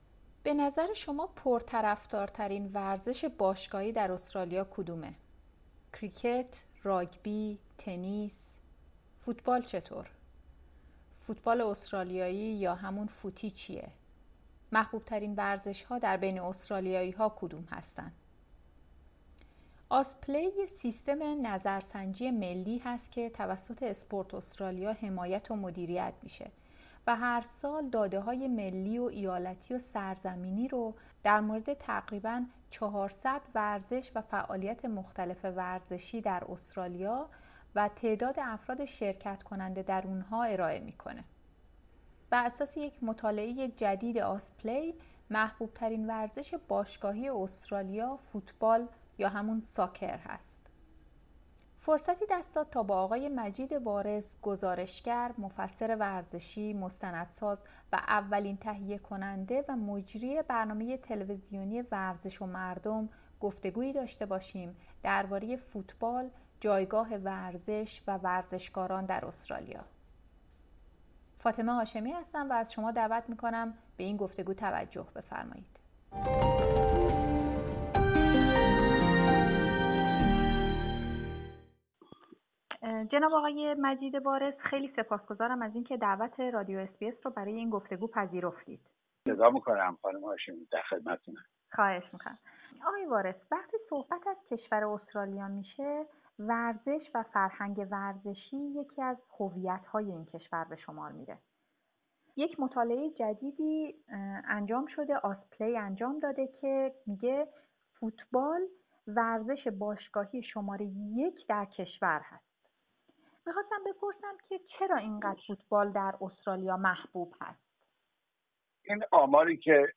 گفتگویی داشتیم درباره فوتبال، جایگاه ورزش و ورزشکاران در استرالیا.